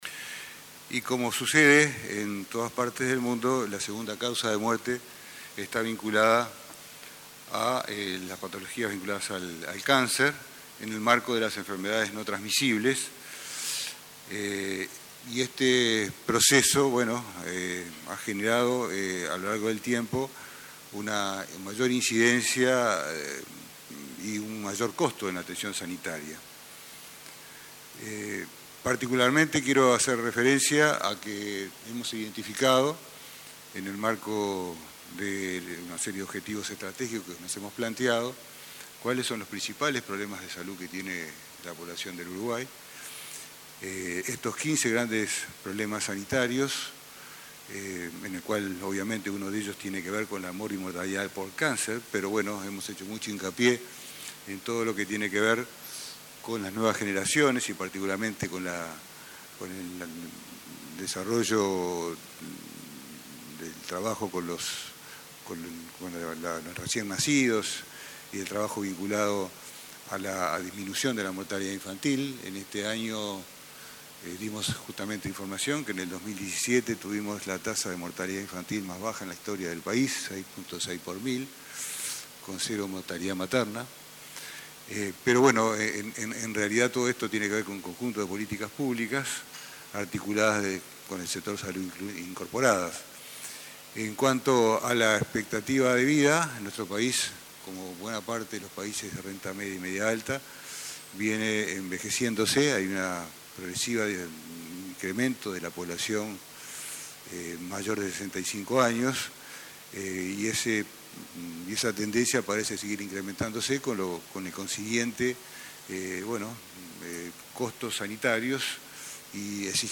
El ministro de Salud Pública, Jorge Basso, destacó el combate al consumo de tabaco, las prestaciones que facilitan la detección precoz y el financiamiento de medicación de alto precio. Dijo que la política antitabaco está creando nuevas generaciones de no fumadores.